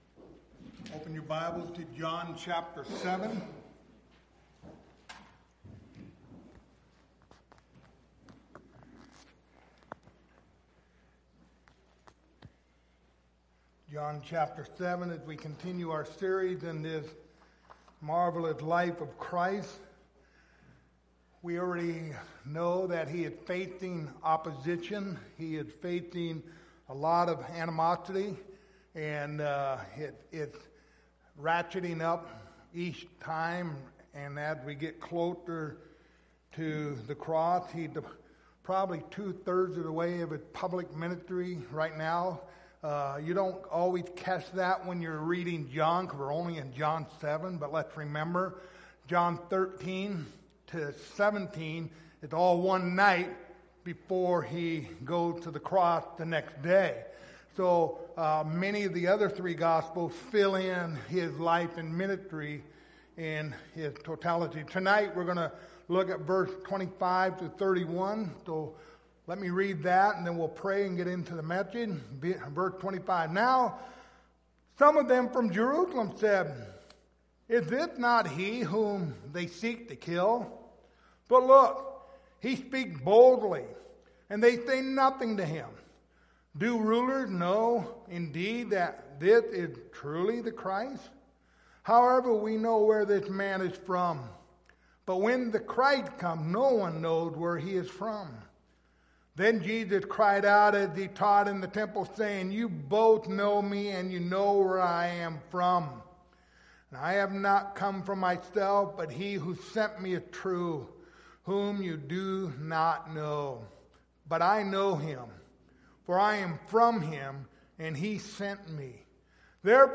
John 7:25-31 Service Type: Wednesday Evening Topics